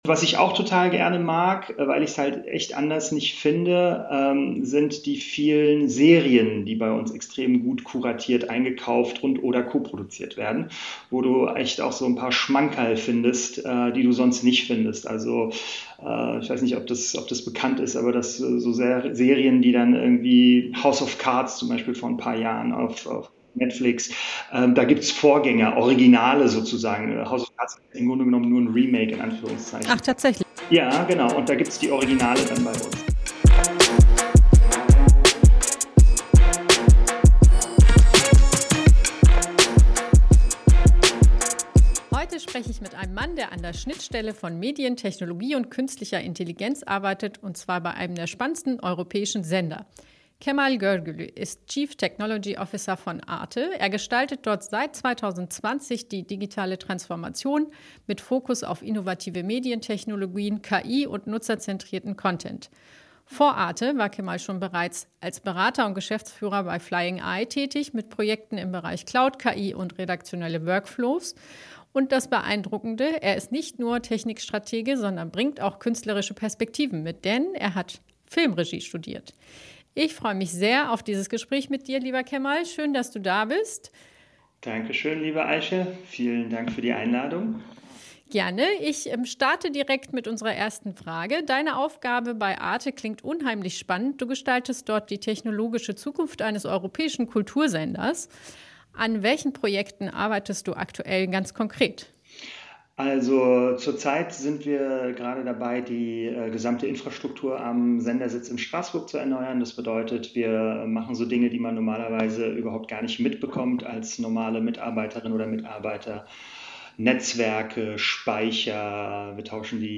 Ein Gespräch über Technologie, Kultur und die Frage, wie ein öffentlich-rechtlicher Sender im KI-Zeitalter relevant bleibt.